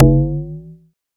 18 CONGA.wav